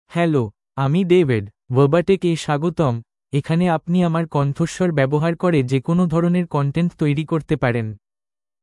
David — Male Bengali (India) AI Voice | TTS, Voice Cloning & Video | Verbatik AI
David is a male AI voice for Bengali (India).
Voice sample
Listen to David's male Bengali voice.
David delivers clear pronunciation with authentic India Bengali intonation, making your content sound professionally produced.